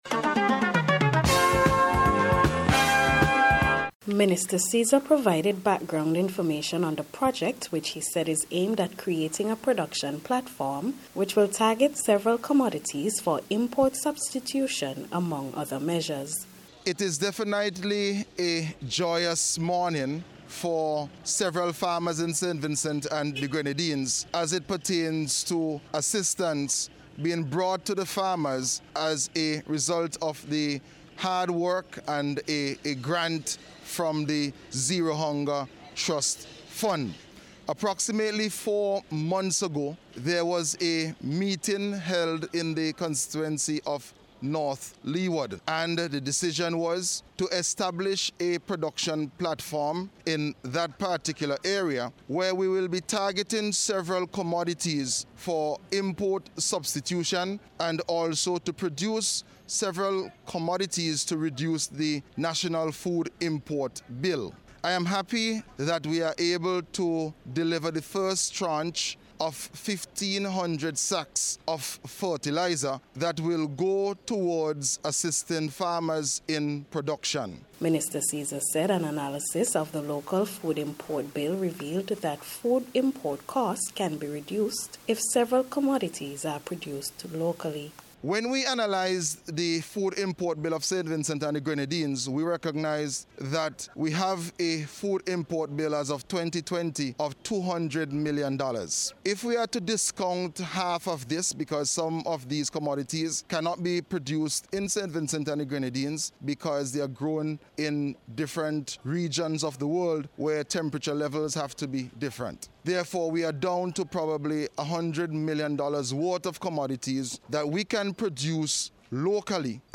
FAMERS-AND-FERTILIZER-REPORT.mp3